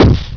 gun2.wav